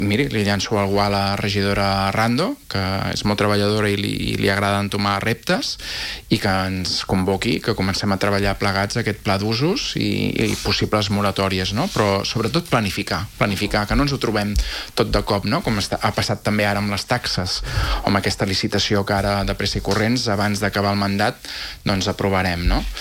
Xavier Ponsdomènech, regidor i portaveu d’ERC, que aquest dilluns ha passat per l’espai de l’entrevista, ha defensat la creació d’un pla d’usos per regular els tipus d’establiments al centre.